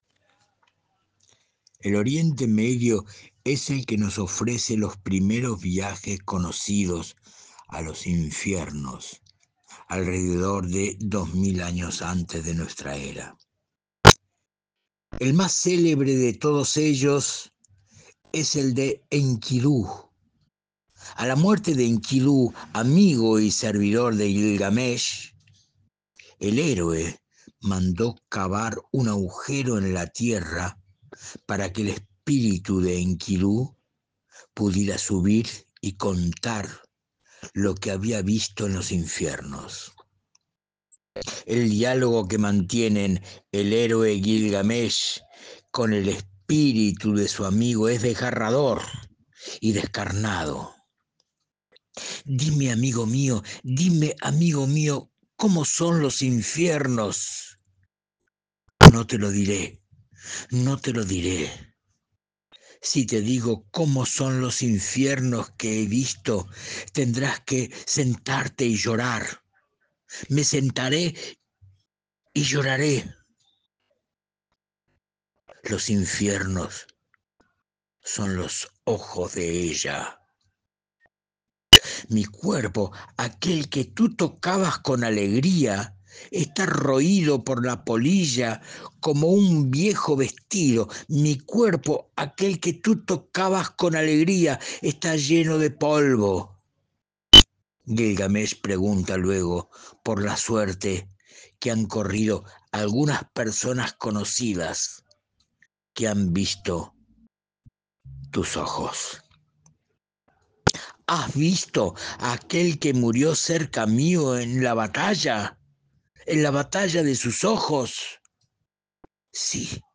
Esperamos que disfruten de estas rarezas poéticas en su voz apasionada.